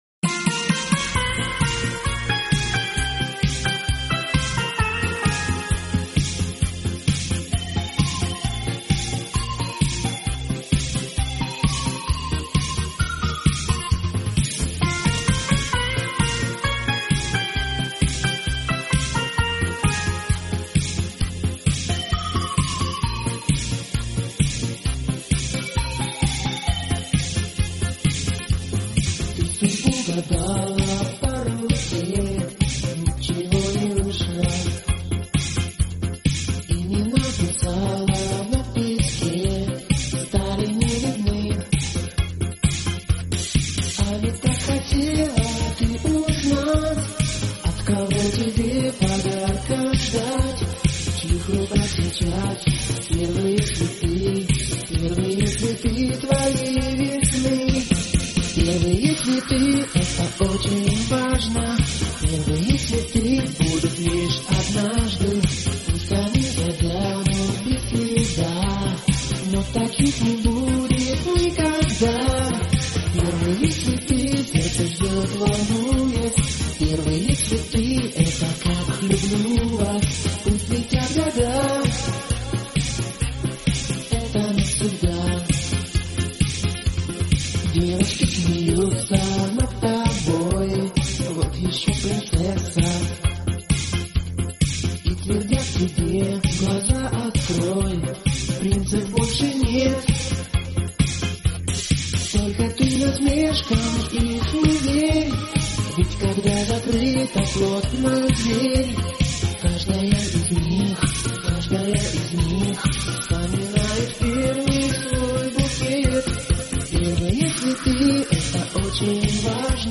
Всі мінусовки жанру Dance
Плюсовий запис